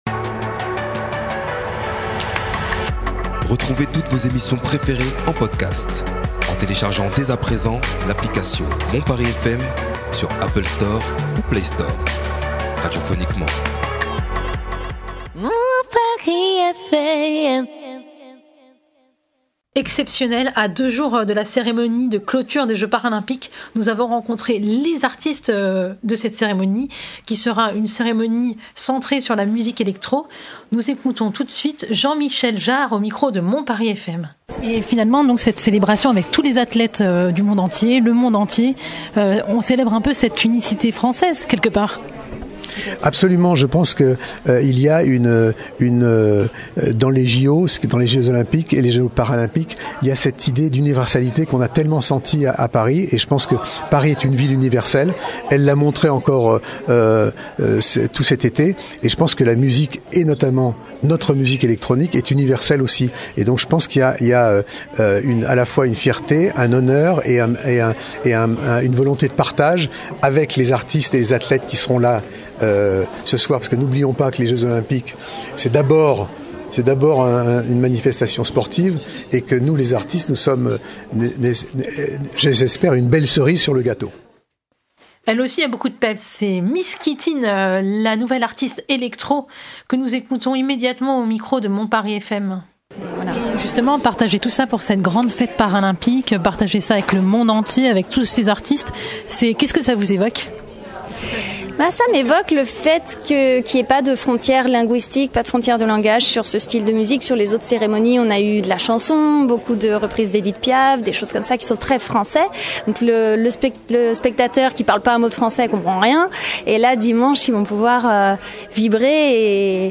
Rencontre avec les artistes de la cérémonie de clôture des Jeux Paralympiques